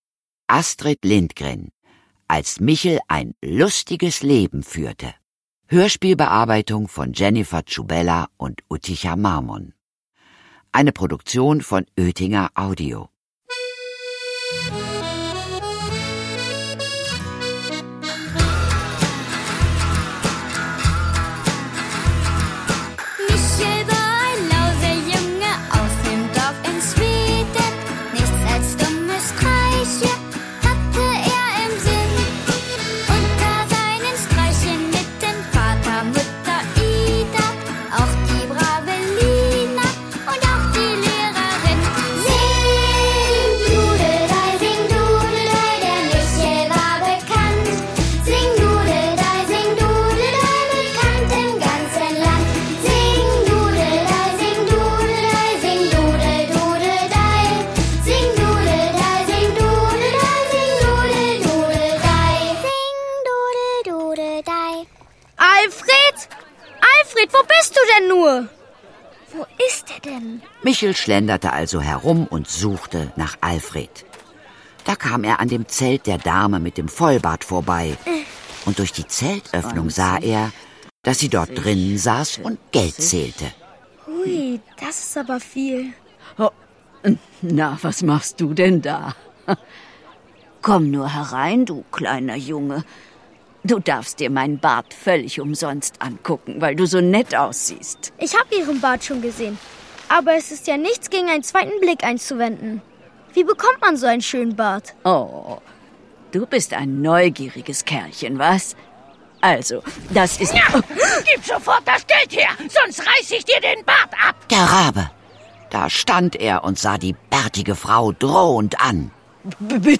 Hörbuch: Als Michel ein "lustiges Leben führte".
Als Michel ein "lustiges Leben führte". Das Hörspiel